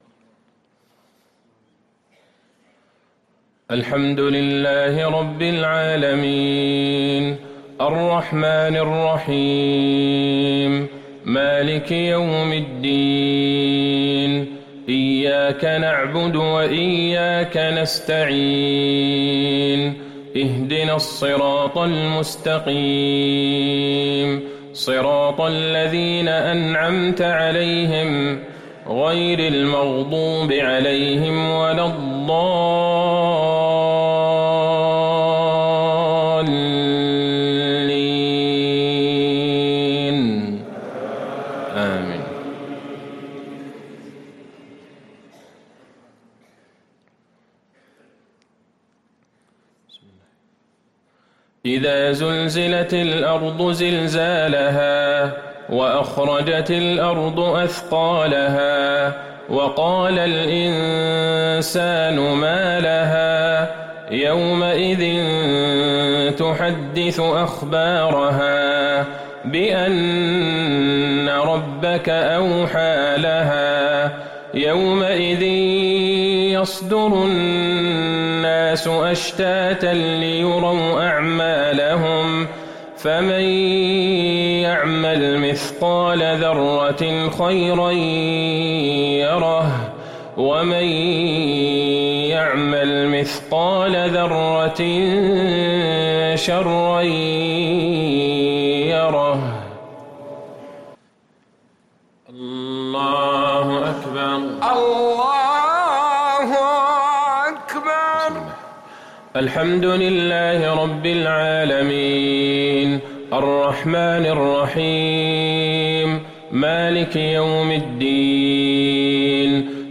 مغرب الأحد 8-6-1444هـ سورتي الزلزلة و القارعة | Maghrib prayer from Surah AZ-zalzalah & Al-Qaria 1-1-2023 > 1444 🕌 > الفروض - تلاوات الحرمين